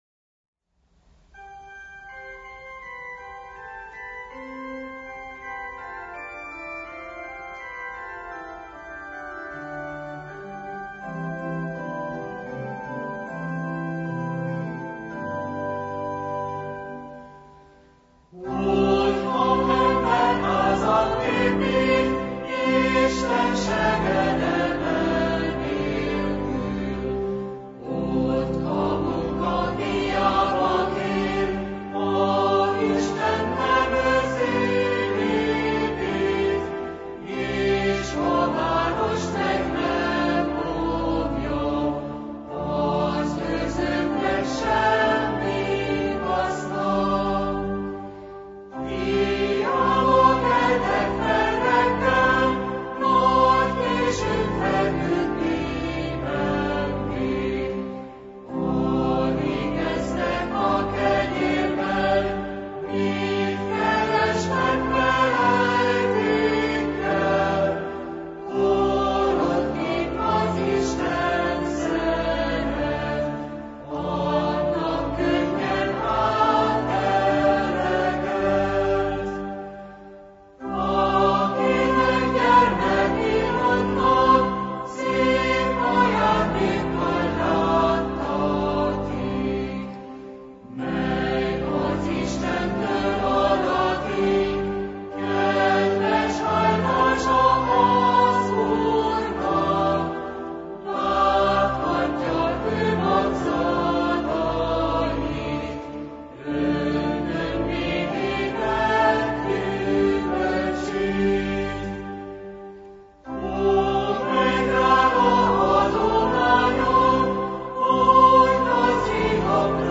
Az év utolsó vasárnapján az egyházi félóra keretében, a clevelandi magyar egyházak vezetői, óév záró és újév köszöntő gondolatai hallgatjuk meg.